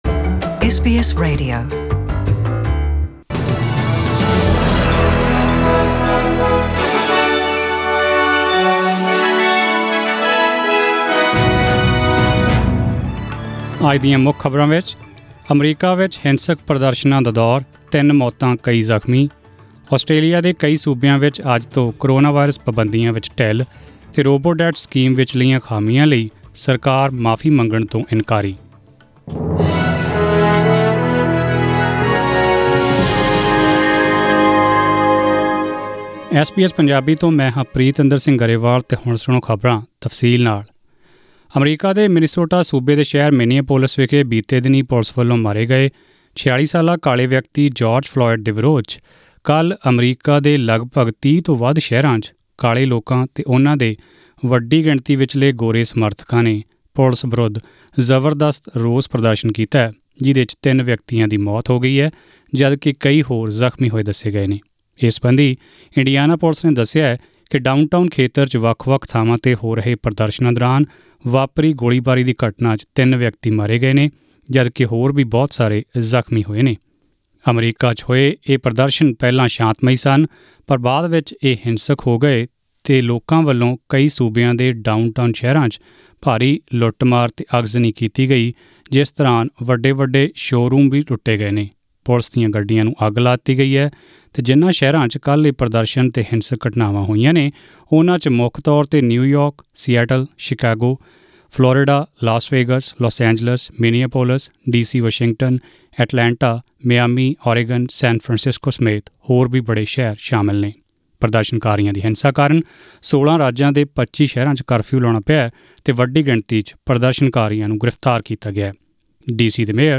Australian News in Punjabi: 1 June 2020